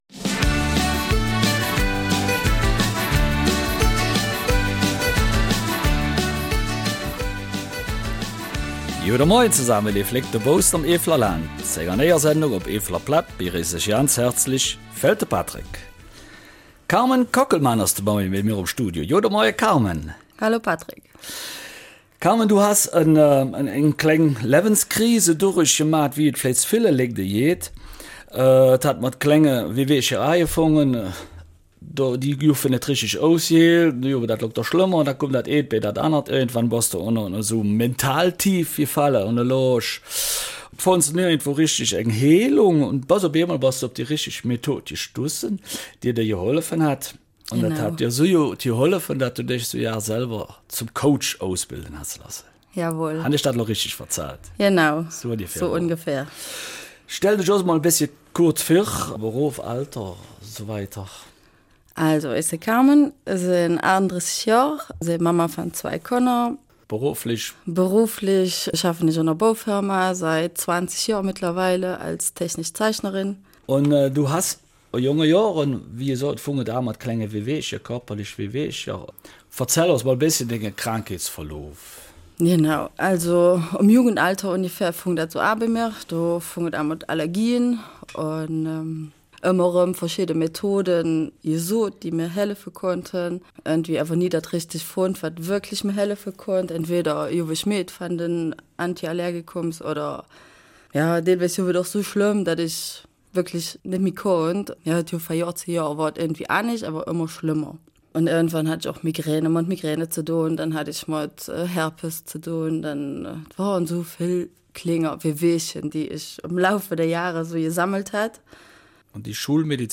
Eifeler Mundart - 9.